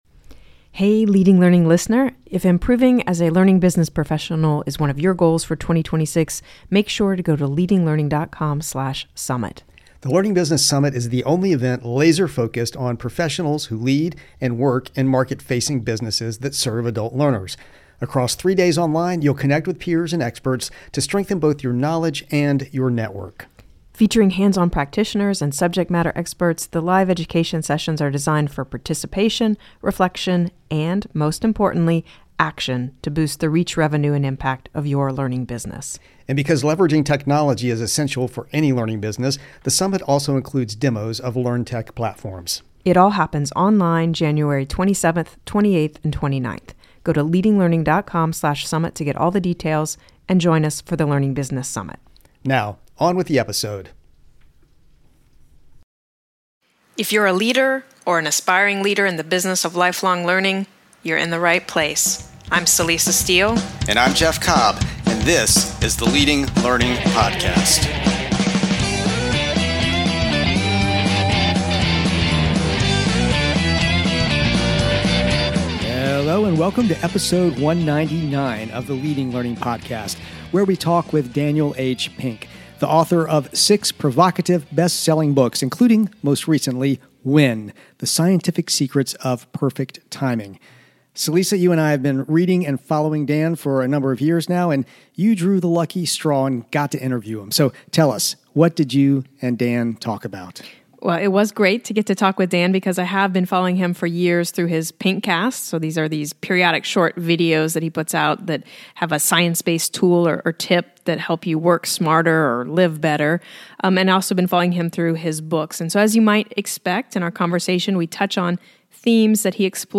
Note: We re-aired this insightful interview in December 2022.